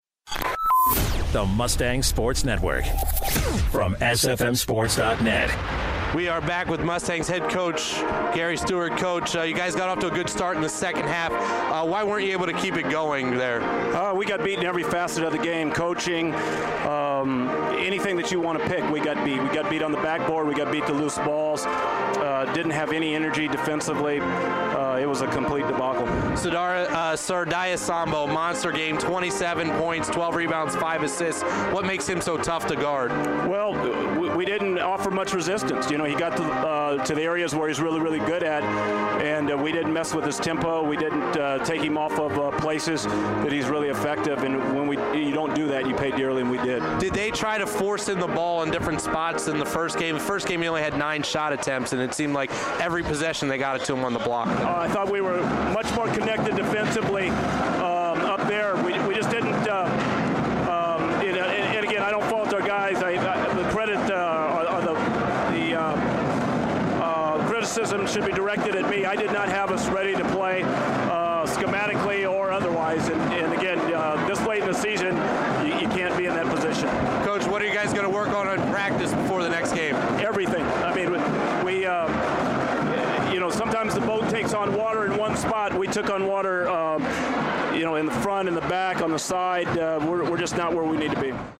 2/6/16: Stevenson Men's Basketball Post Game Show